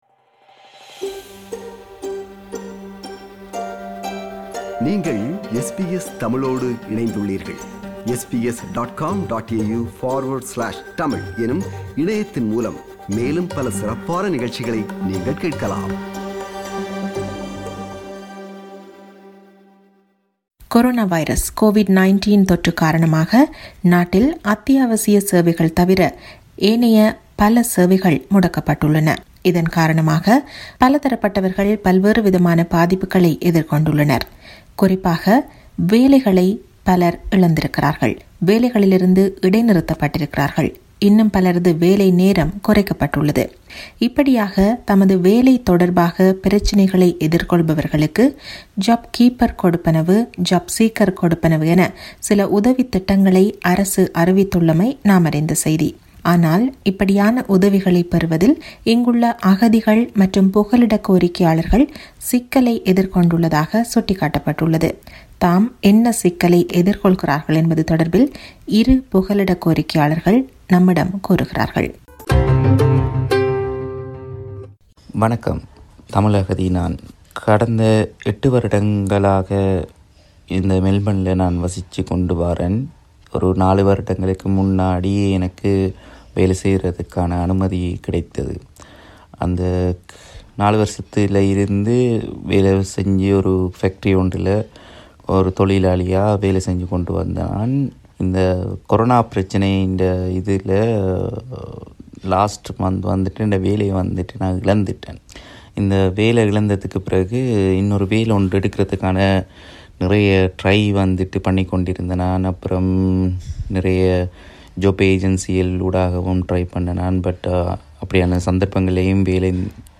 Australia’s temp visa holders are ineligible for the government's financial assistance. Many are unable to return to their country of origin and are living on their last dollar. Asylum seekers share their experience with us.